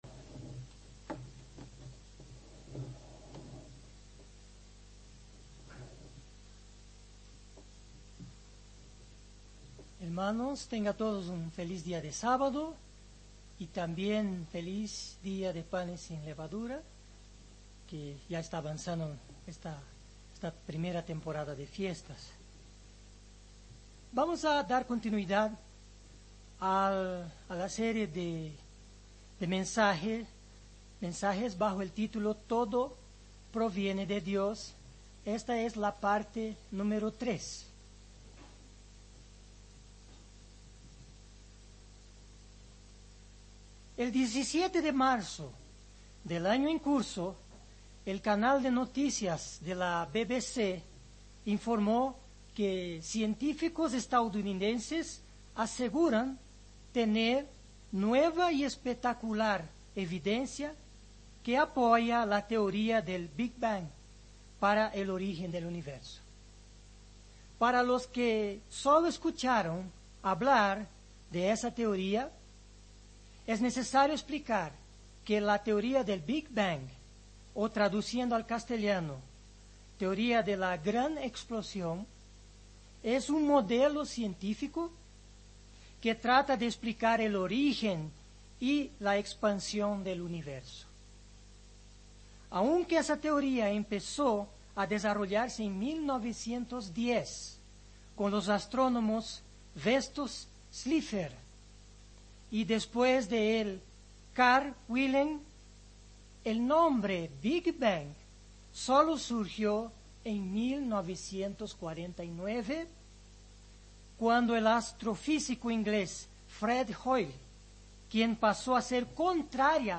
Given in Ciudad de México